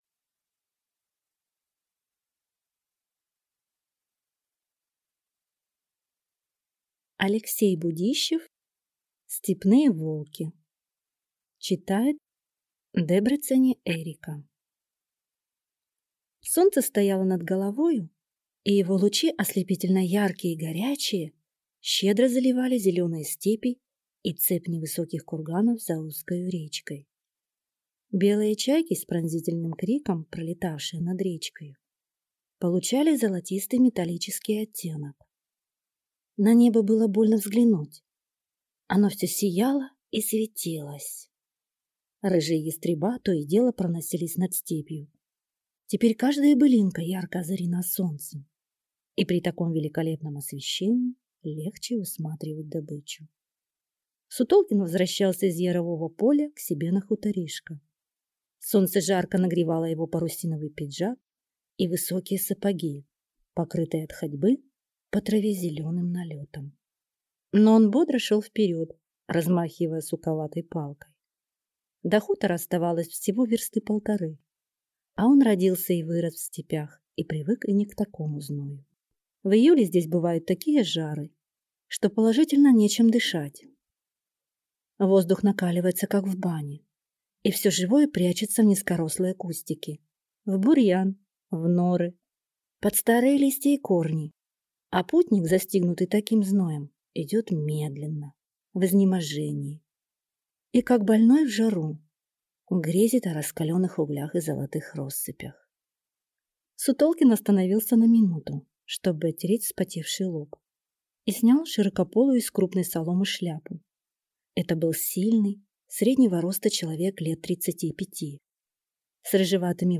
Аудиокнига Степные волки | Библиотека аудиокниг